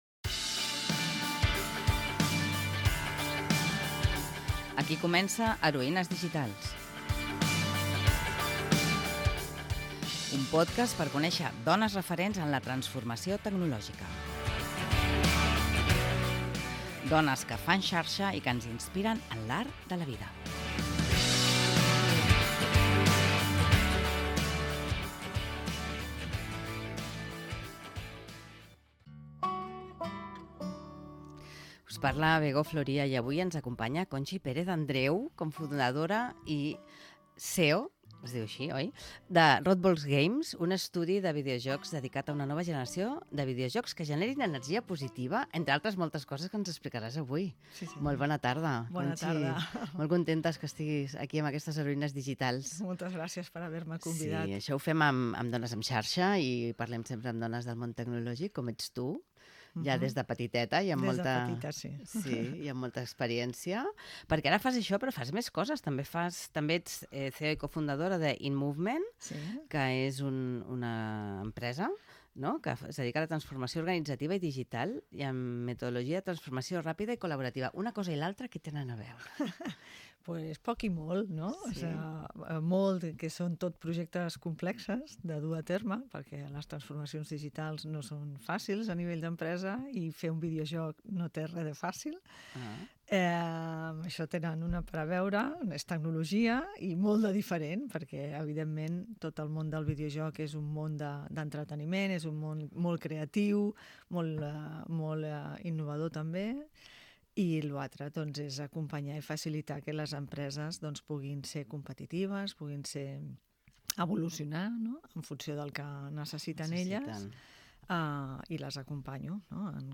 Presentació del programa i entrevista